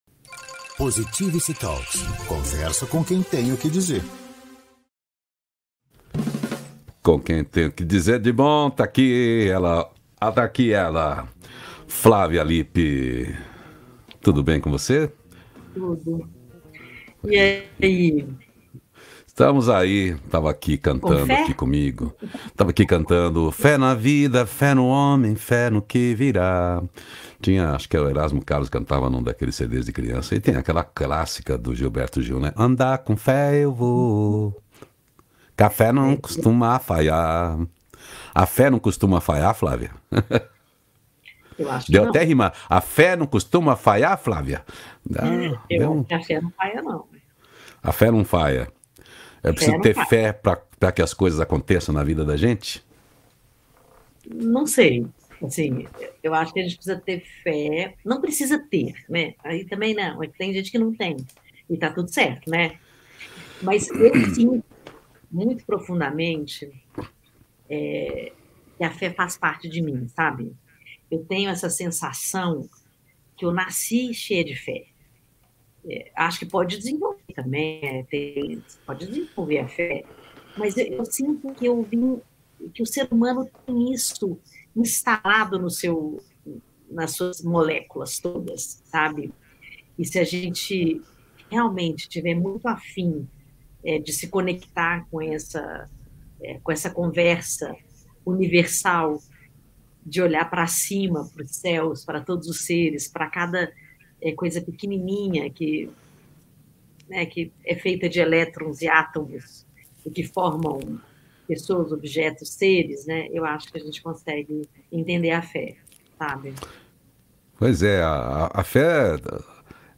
Um diálogo sobre ter Fé